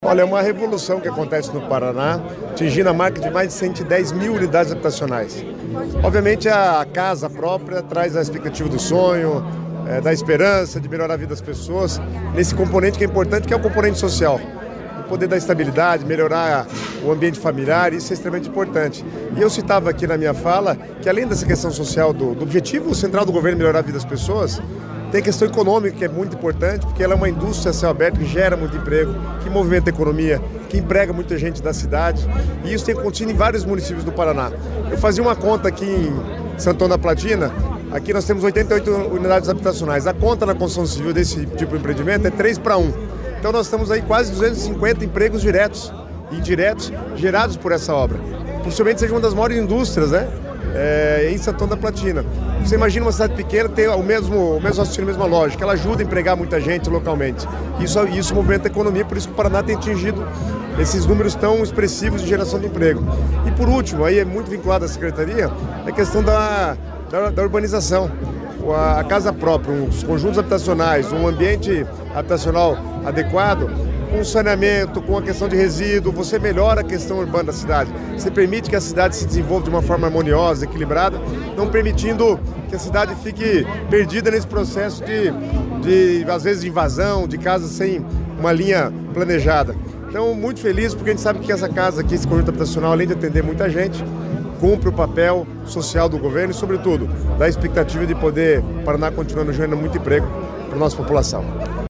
Sonora do secretário das Cidades, Guto Silva, sobre a entrega de apartamentos em Santo Antônio da Platina